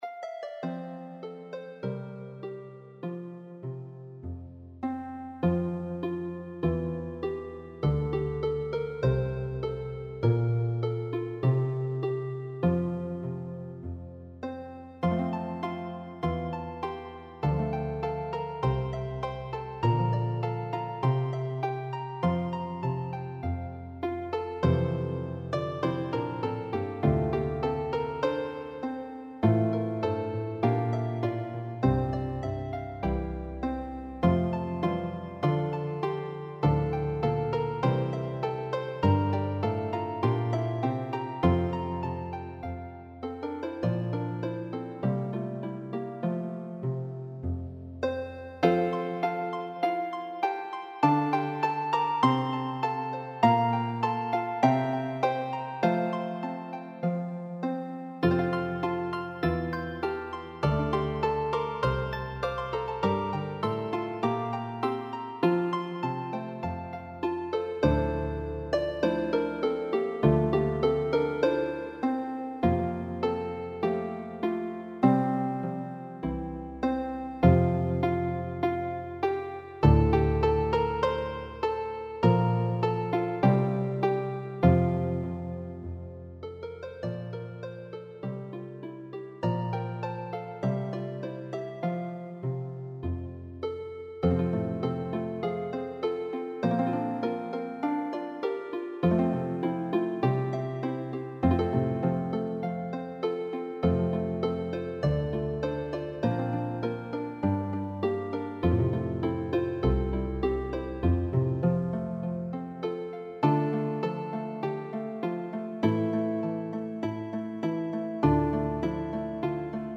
is for four lever or pedal harps